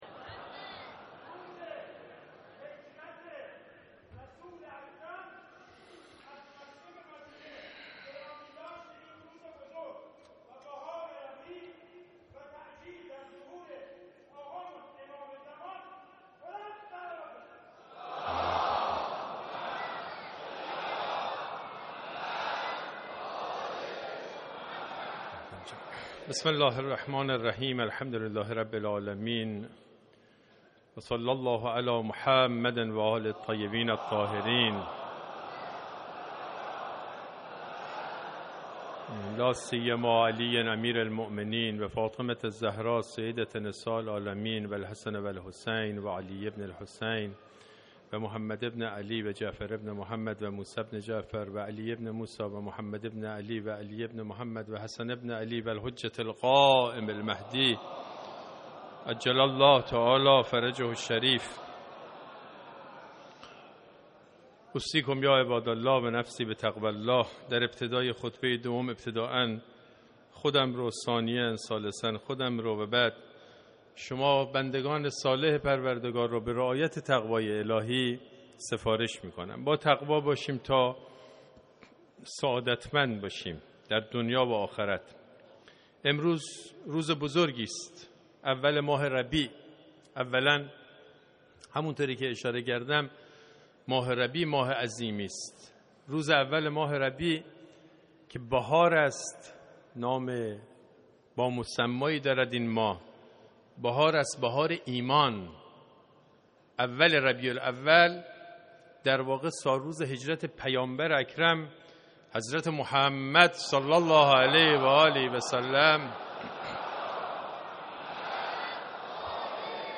خطبه دوم